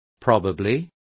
Complete with pronunciation of the translation of probably.